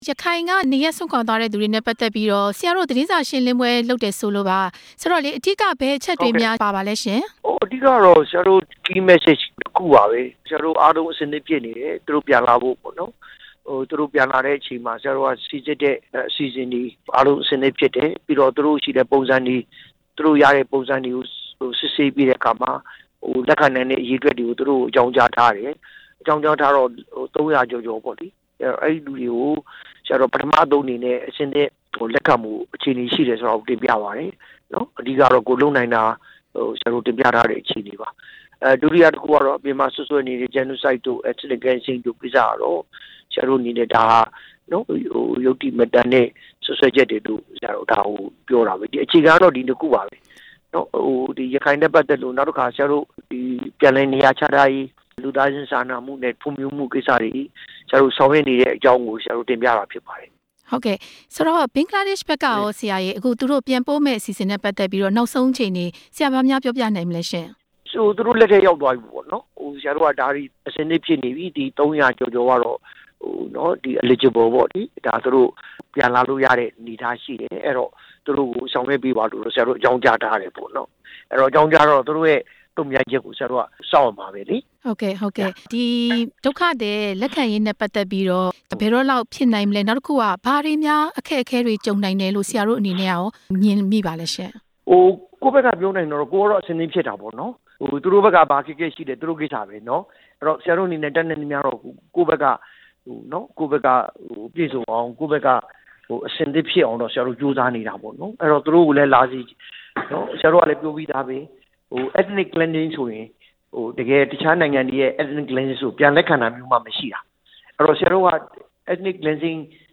ထွက်ပြေးသွားသူတွေ ပြန်လက်ခံရေး ဒေါက်တာအောင်ထွန်းသက် နဲ့ မေးမြန်းချက်